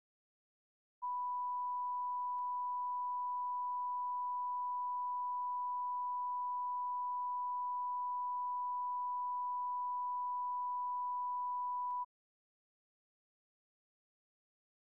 Secret White House Tapes
Location: White House Telephone
Charles W. Colson talked with the President.
[Unintelligible]